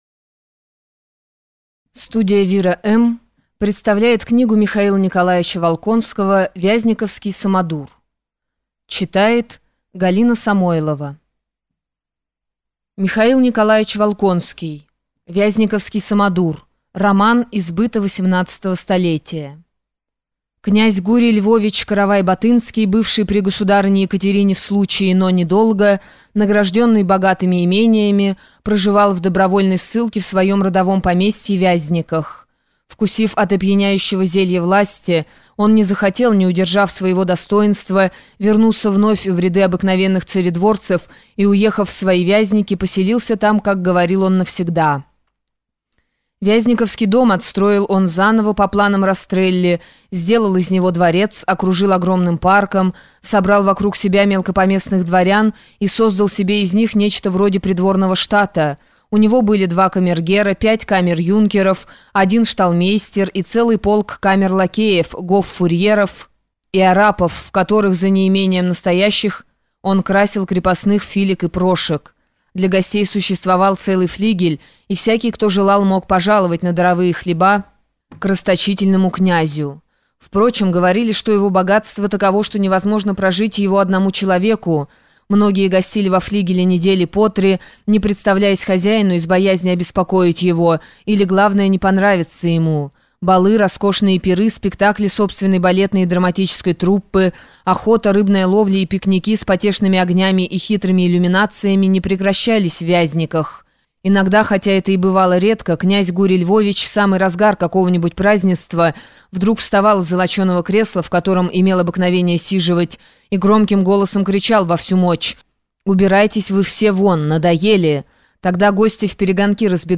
Скачать, слушать онлайн аудиокнигу Вязниковский самодур автора Волконский Михаил Николаевич